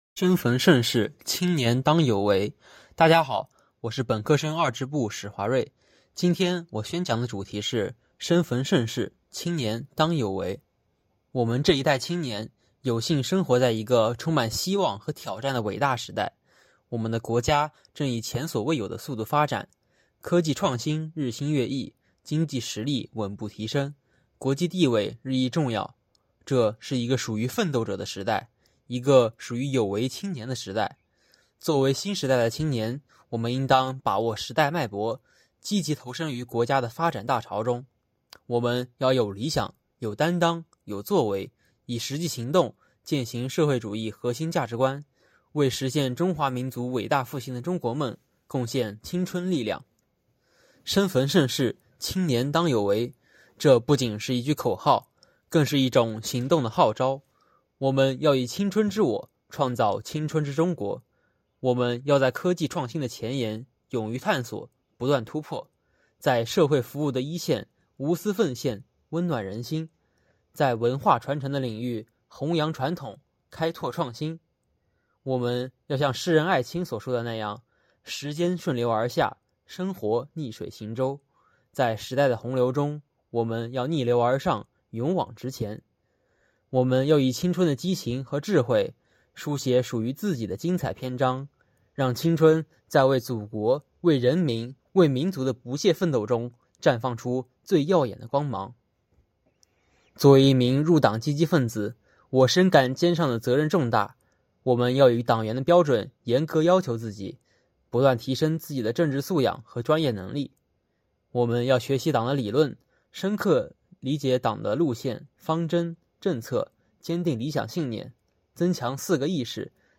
为引导广大教师和学生党员坚定理想信念，厚植爱国情怀，Beat365唯一官方网站分党校鼓励全体学员人人讲党课，开展“三分钟微党课”特色宣讲活动，展现分党校学员的示范引领作用，把党课融入日常、做在经常，把学习贯彻习近平新时代中国特色社会主义思想不断引向深入。